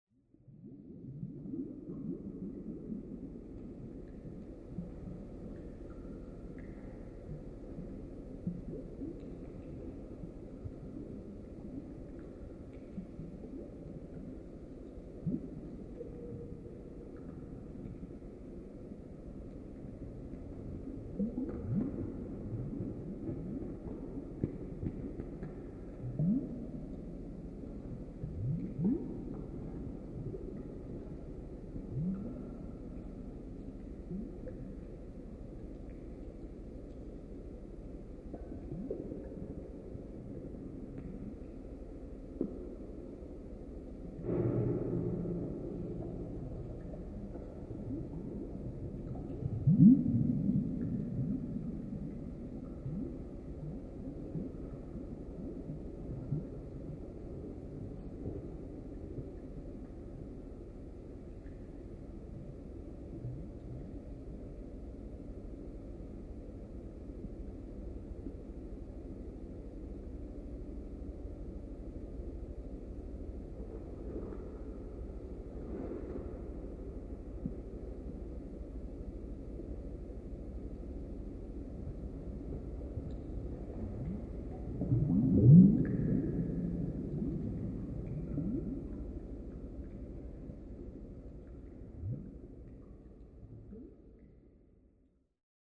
Schlamm MP3
Während meiner Bachwanderung konnte ich hunderte alltäglicher, aber auch skurriler Klänge mit Mikrofon und digitalem Recorder aufnehmen.
Nicht immer ist die Höhe zu "hören", verändert sich dieser Klang doch ständig in seiner Klangcharakteristik und Lautstärke, doch prinzipiell ist er ständig präsent.
Verschlammte Bachabschnitte
Sie sind akustisch seperat vermerkt....